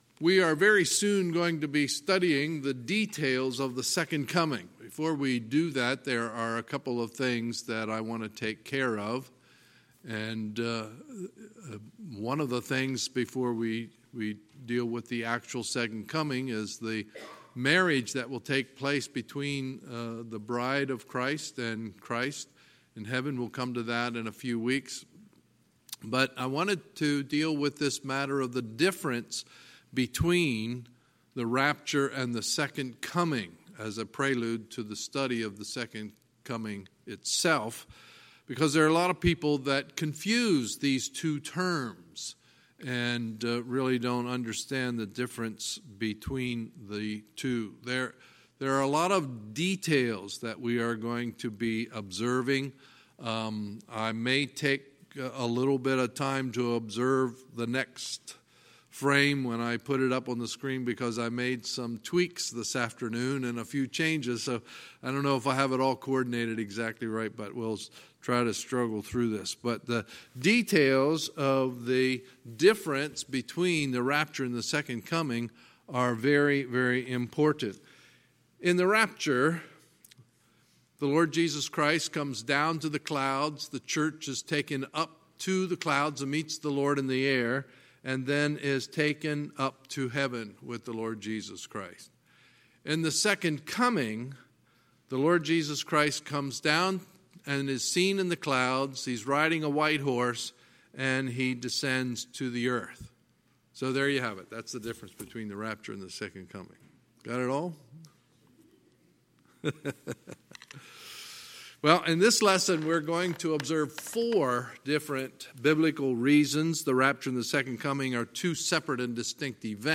Sunday, May 26, 2019 – Sunday Evening Service